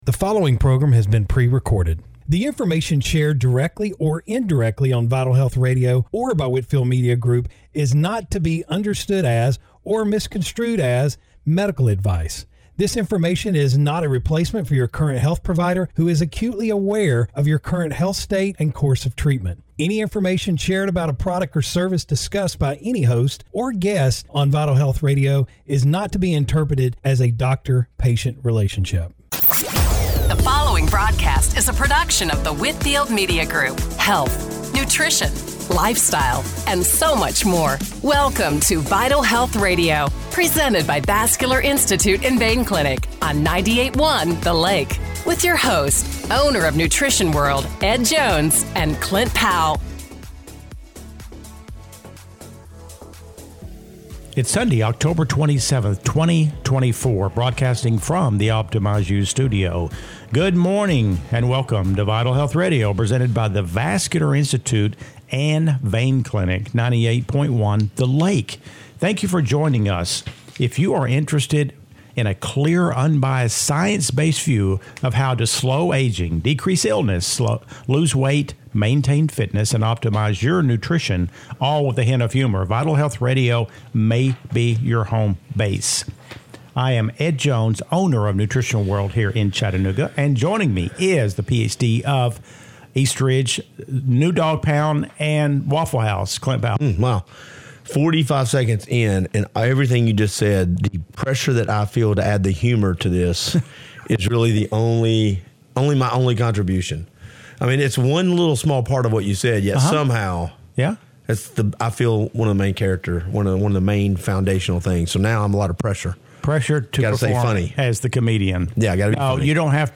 Radio Show – October 27, 2024 - Vital Health Radio